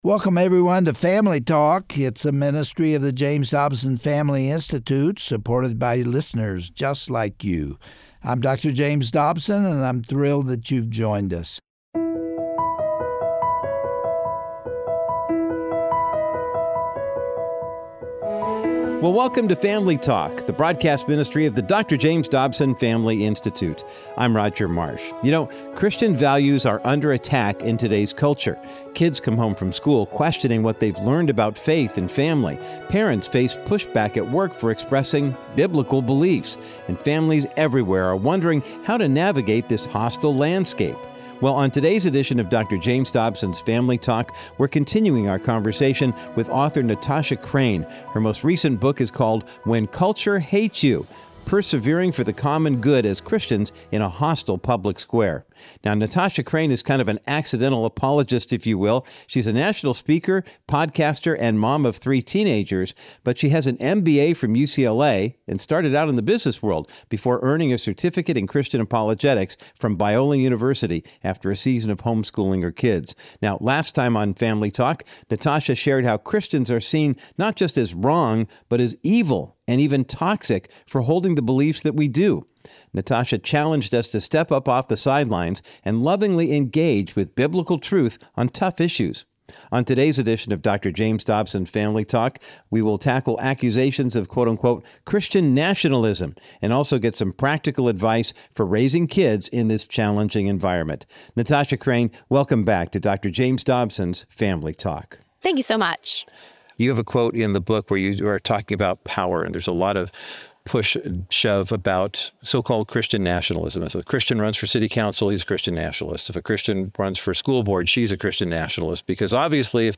1 A Biblical Lens on the Conflict in Israel - Part 2 Play Pause 2d ago Play Pause Spela senare Spela senare Listor Gilla Gillad — On today’s edition of Family Talk, Gary Bauer and the Hon. Michele Bachmann conclude their discussion on the continued war in Israel.